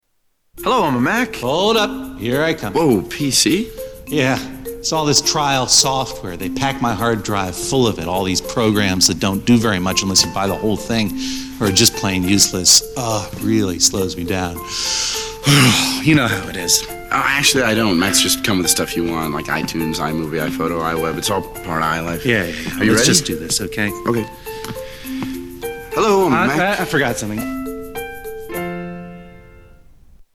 Tags: Media Apple Mac Guy Vs. PC Guy Commercial Justin Long John Hodgeman